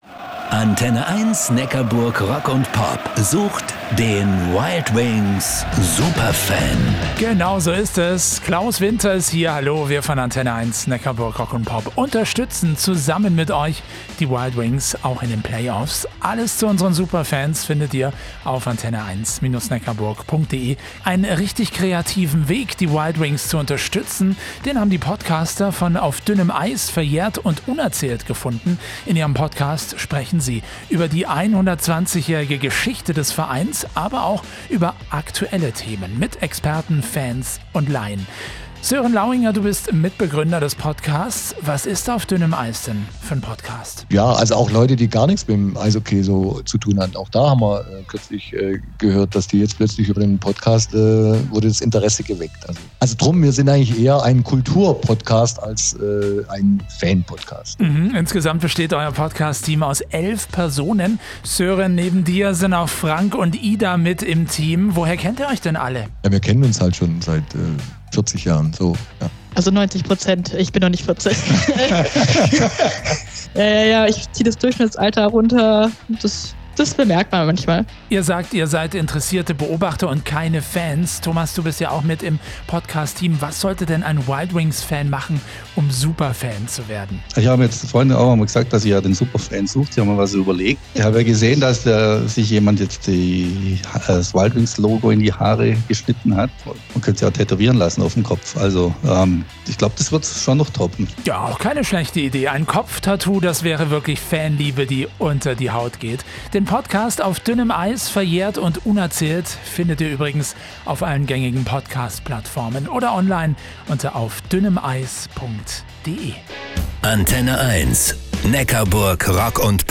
Radio antenne1 Neckarburg Rock & Pop hat zwei Beiträge über unseren Podcast ausgestrahlt: